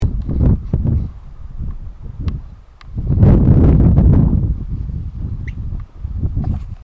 Carqueja-de-bico-manchado (Fulica armillata)
Nome em Inglês: Red-gartered Coot
Fase da vida: Adulto
Localidade ou área protegida: Valle Inferior del Río Chubut (VIRCH)
Condição: Selvagem
Certeza: Observado, Gravado Vocal
gallareta-ligas-rojas.mp3